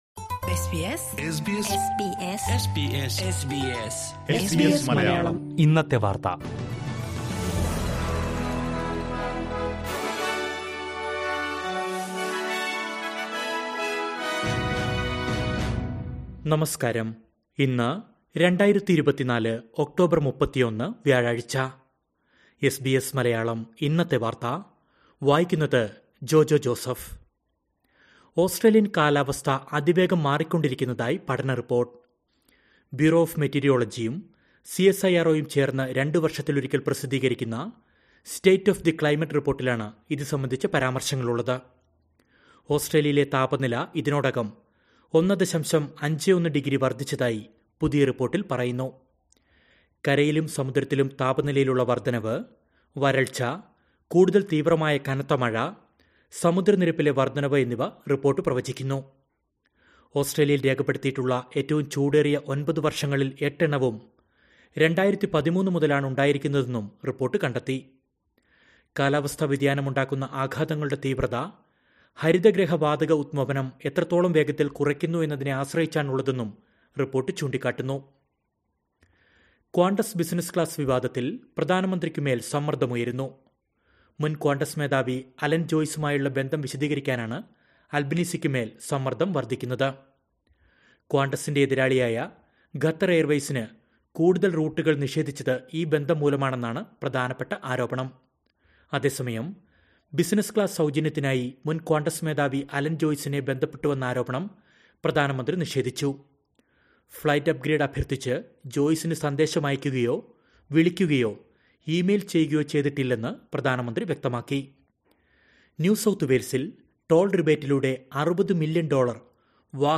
2024 ഒക്ടോബര്‍ 31ലെ ഓസ്‌ട്രേലിയയിലെ ഏറ്റവും പ്രധാന വാര്‍ത്തകള്‍ കേള്‍ക്കാം...